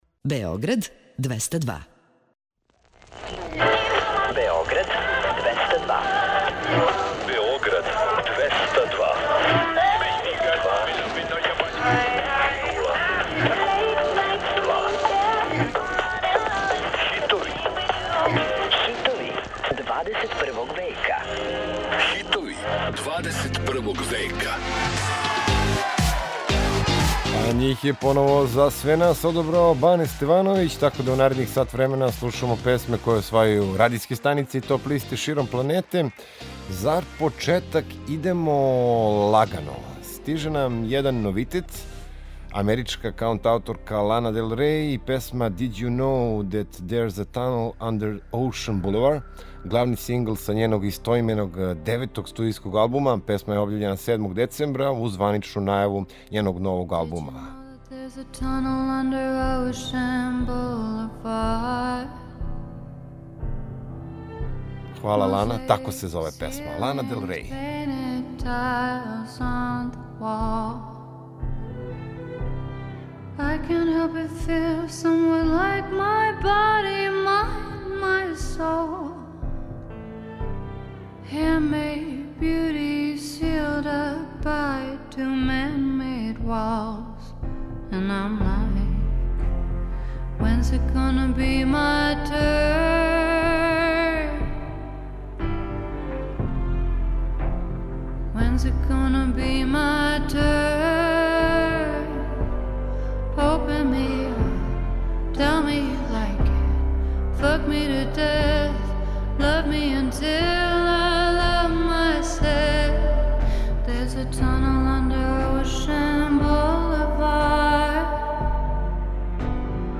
Чућете песме које се налазе на врховима светских топ листа.